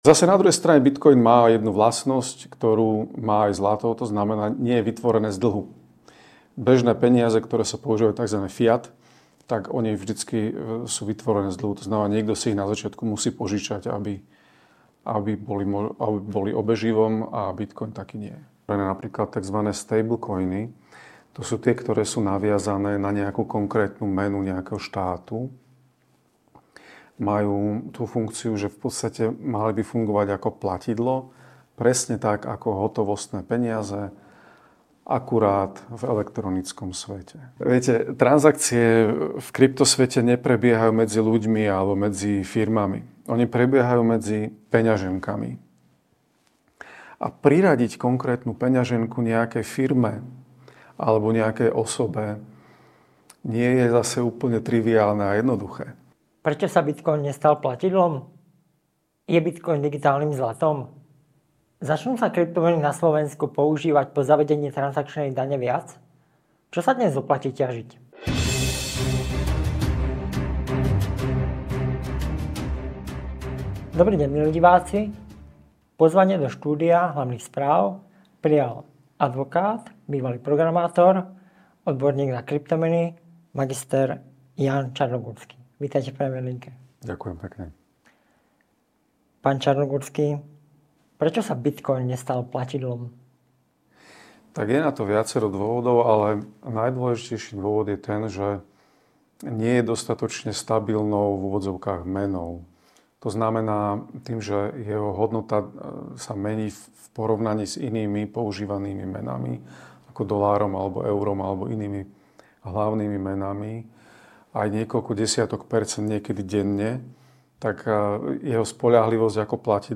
Bitcoin sa nestal platidlom z istých dôvodov, začal náš rozhovor odborník na kryptomeny.